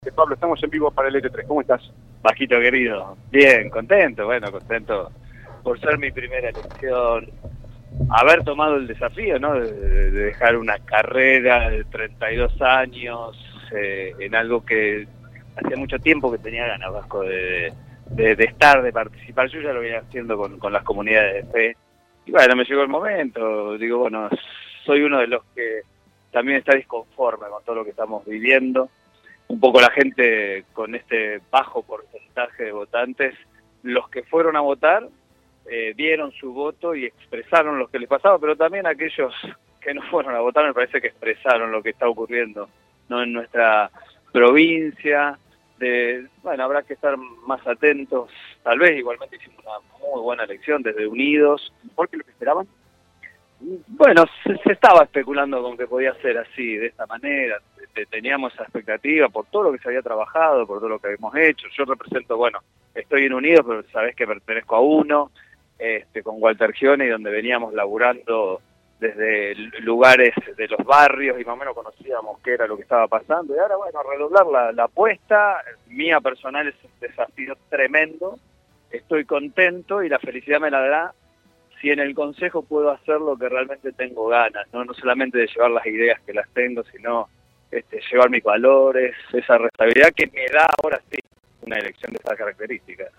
Pablo Gavira, número 3 en la lista que presenta el Frente Unidos para el Concejo de Rosario habló desde el centro de campaña y se mostró contento con los resultados que lo posicionan como nuevo concejal de la ciudad.